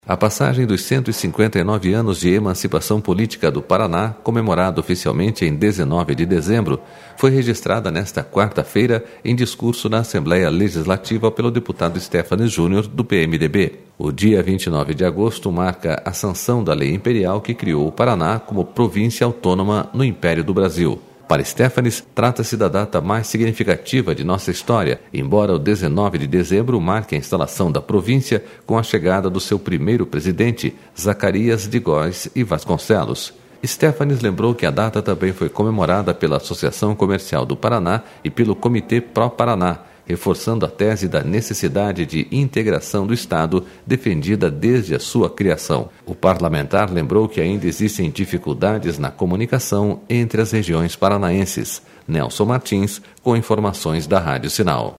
A passagem dos 159 anos da emancipação política do Paraná, comemorada oficialmente em 19 de dezembro, foi registrada nesta quarta-feira em discurso na Assembleia Legislativa, pelo deputado Stephanes Junior, do PMDB.//O dia 29 de agosto marca a sanção da Lei Imperial que criou o Paraná como província...